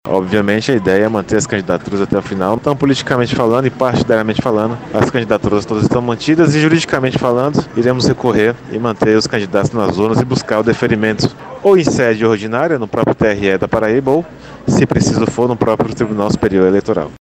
destacou durante entrevista ao Correio Debate que ainda cabem recursos quanto a decisão e apostou na manutenção dos nomes do PCO na disputa.